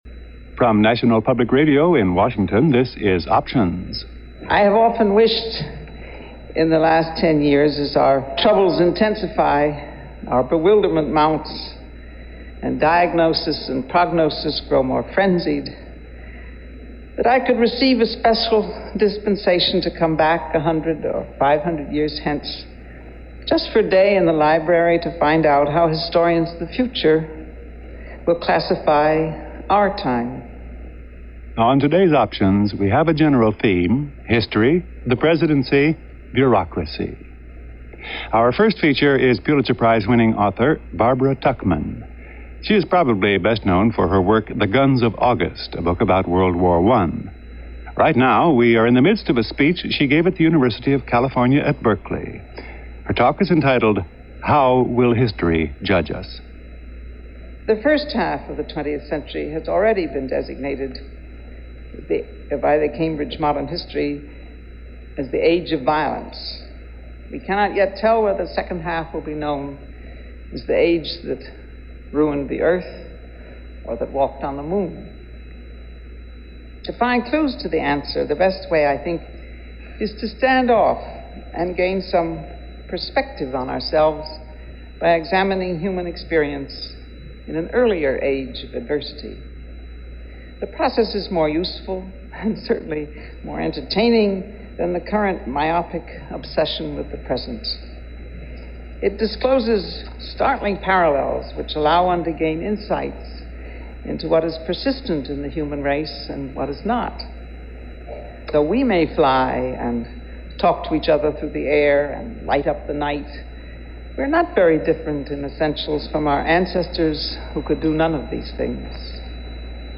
Barbara Tuchman address at UC Berkeley
Listening to this address, given by noted author and historian Barbara Tuchman, I came away with the feeling not very much will weigh in as far as milestones go in the future.
barbara-tuchman-speaking-at-uc-berkeley-options-1974.mp3